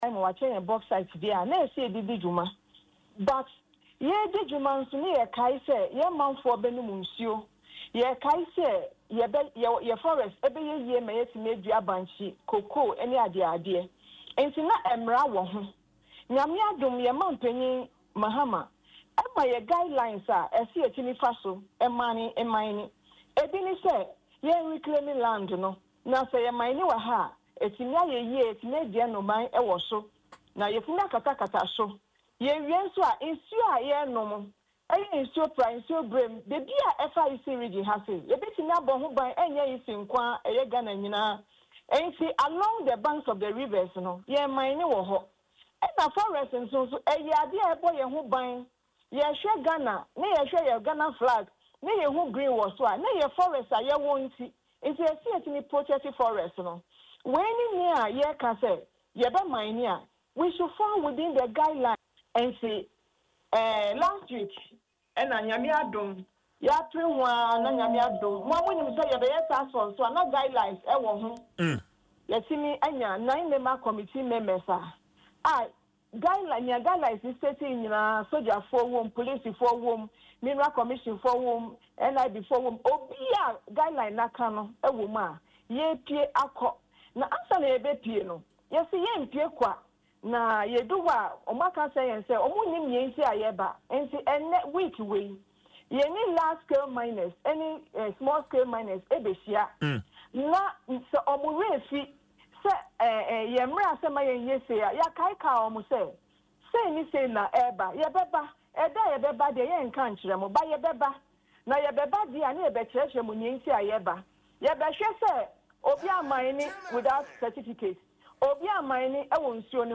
“We know there are laws, and we must obey them. If you are mining without a license and the task force comes after you, they will act strictly according to the law,” she said in an interview on Adom FM’s morning show Dwaso Nsem.